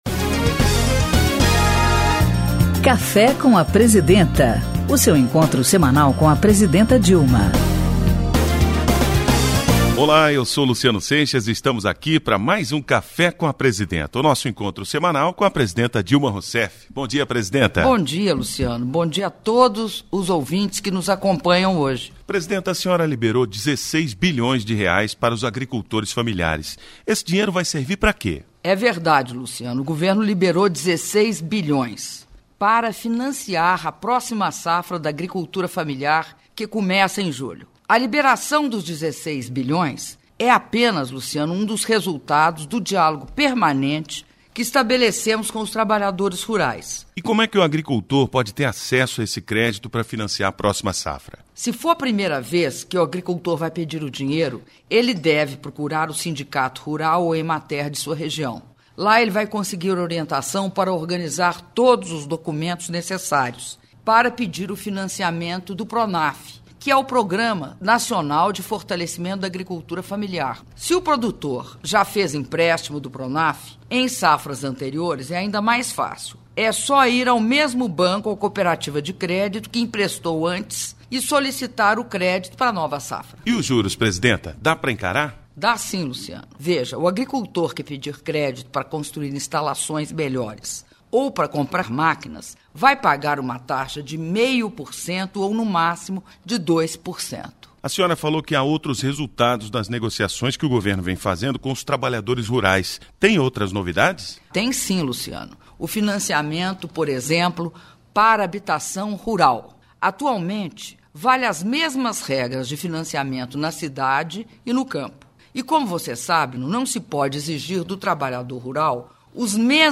programa de radio 201ccafe com a presidenta201d com a presidenta da republica dilma rousseff radio nacional 23 de maio de 2011